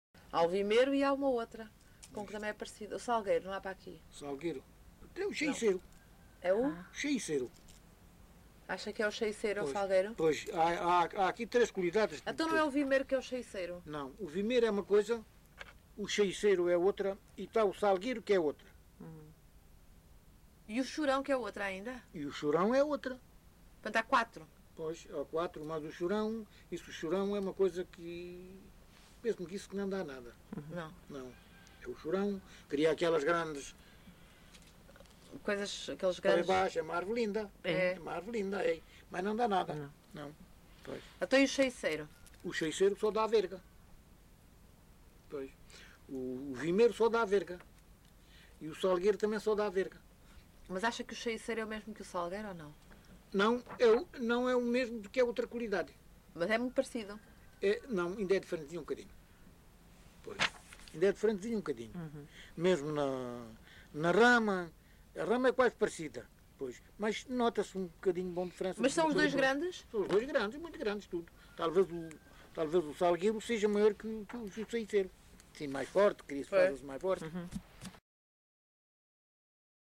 LocalidadeVale Chaim de Baixo (Odemira, Beja)